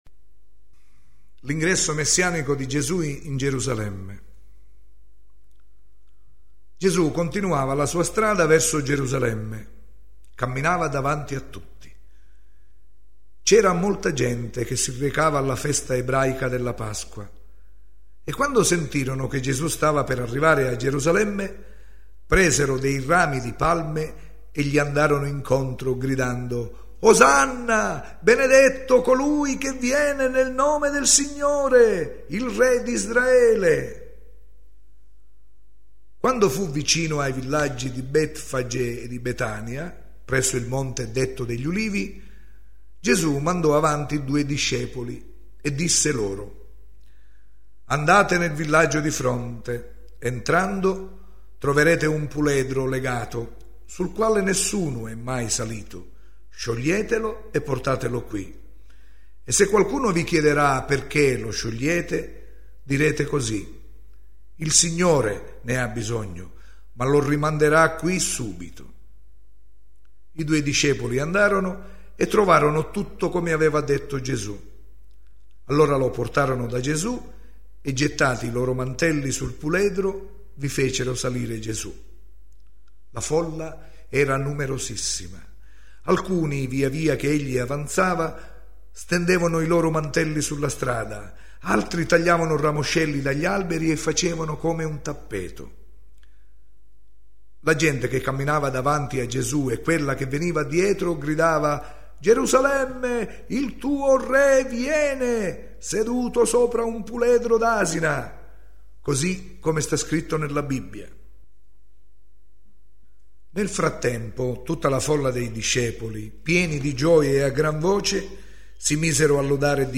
Ascolta il brano letto